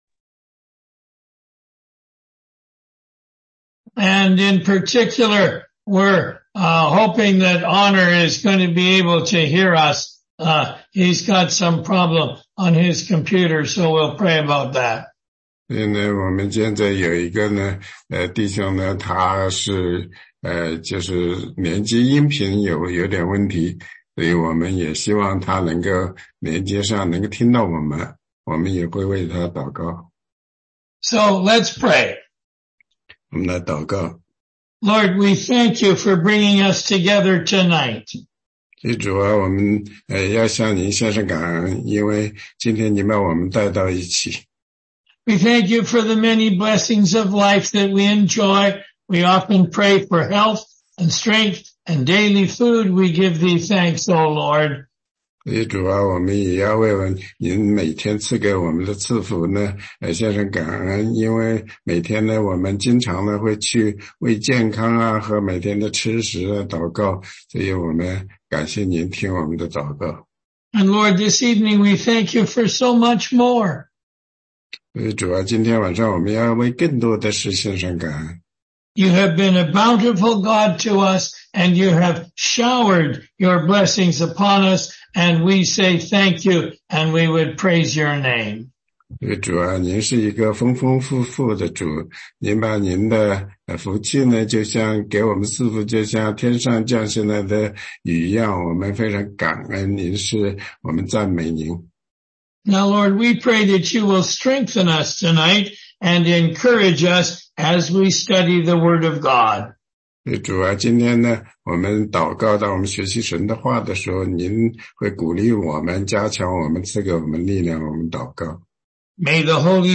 16街讲道录音 - 福音课第七十五讲（中英文）